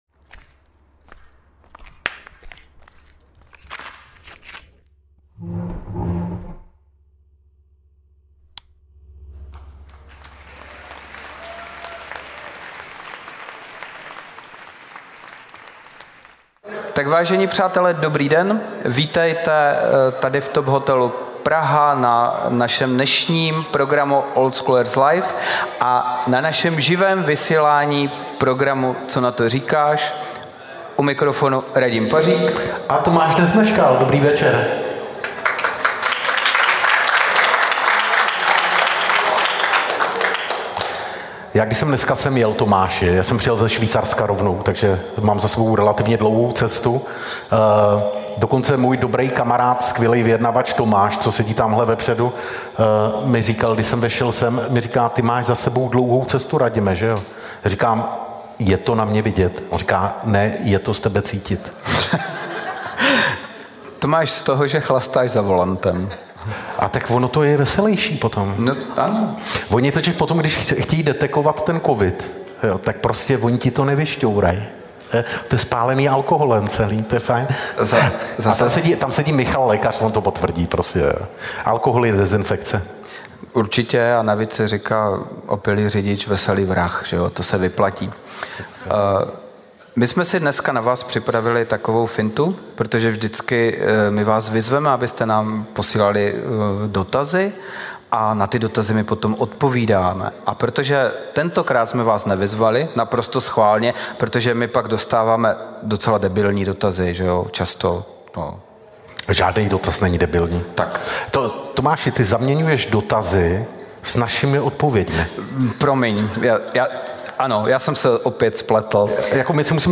Tento díl je speciální. Program s živým obecenstvem.